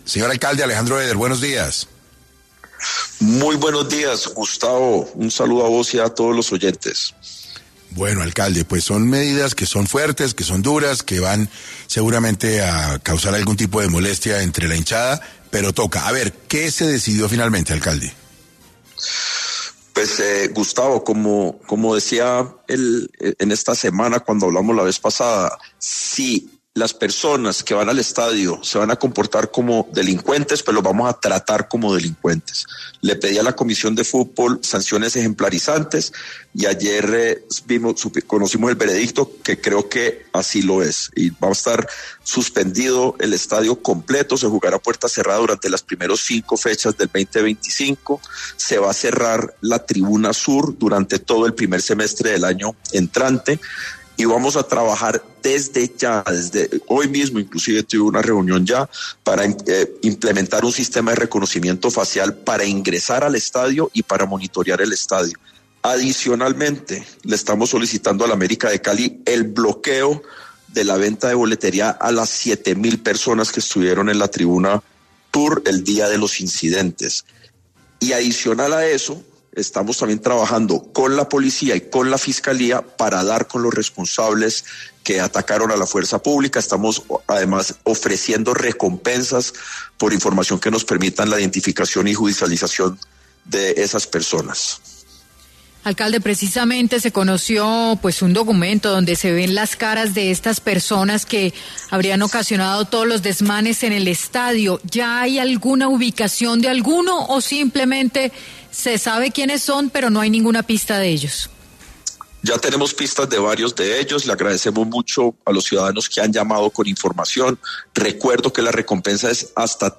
En 6AM de Caracol radio estuvo el alcalde de Cali, Alejandro Eder, para hablar sobre cuáles serán las medidas que tendrán los barristas que protagonizaron disturbios durante final de la Copa en el pascual guerrero.